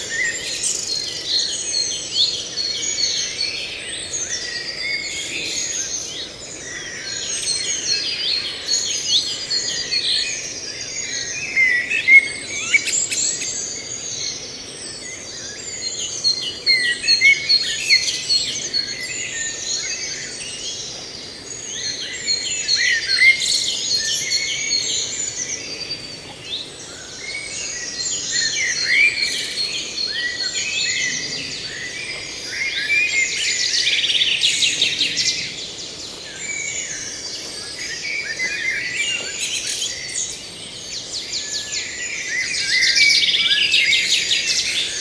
birds_screaming_loop.ogg